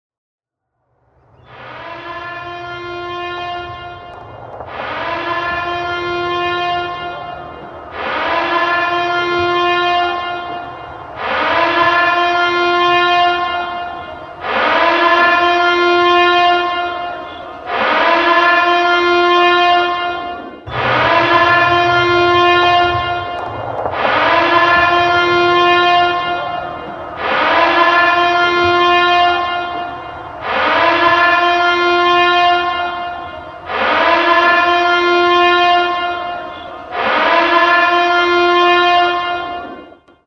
blowout_siren_jup1.ogg